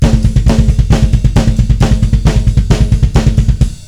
Black Metal Drum Rudiments
Beat 3 - The Bass Ruff
bassruf3.wav